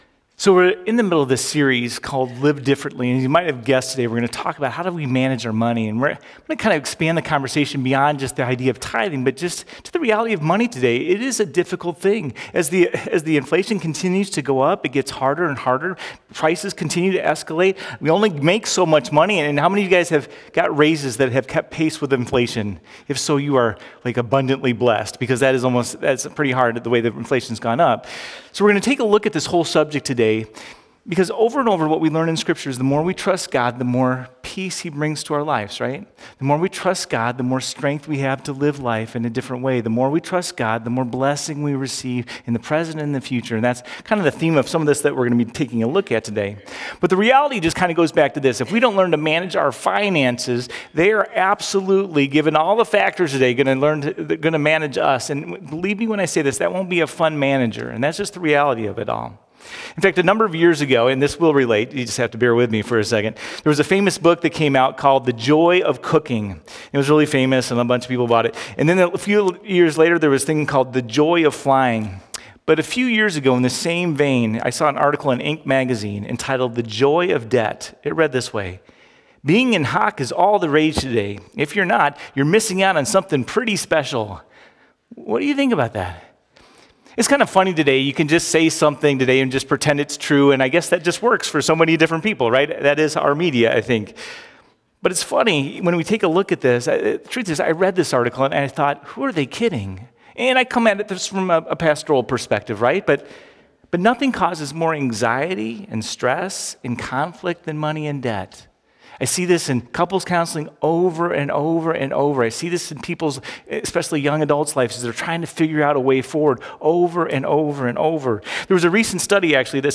310-Sermon.mp3